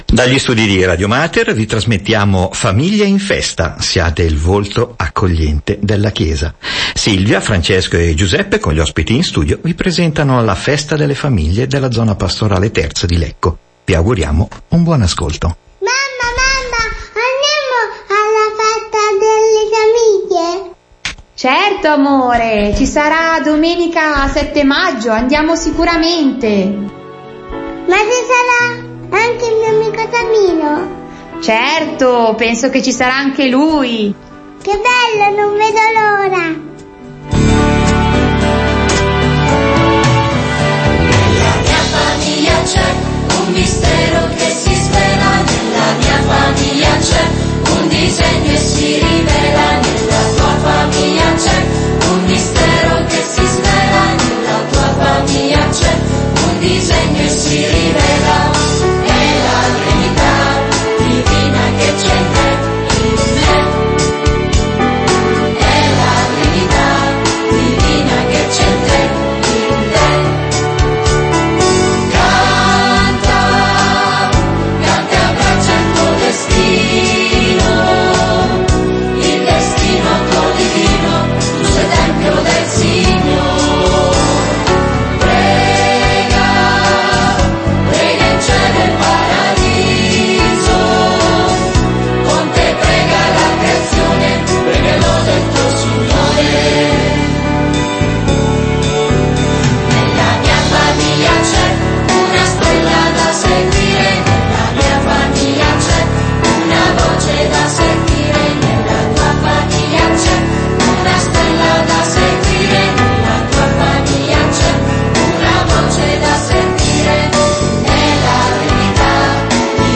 incontrano in studio